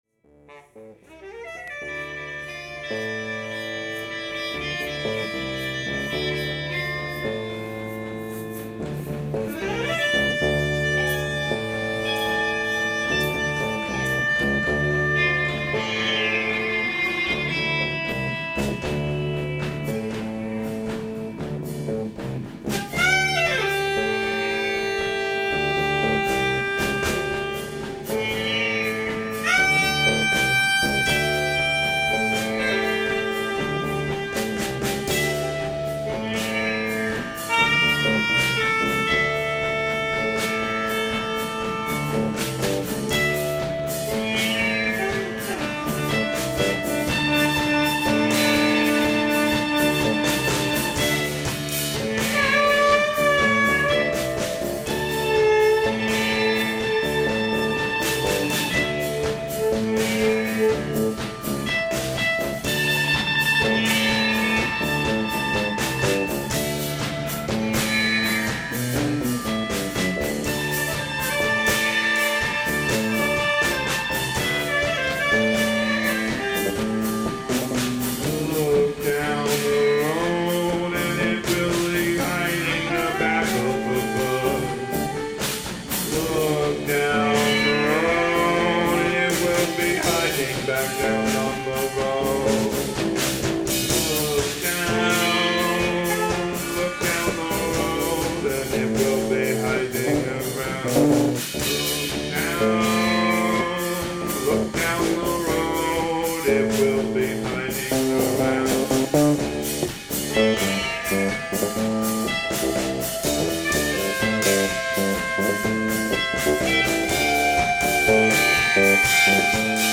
guitar/voice
bass
drums
sitar
sax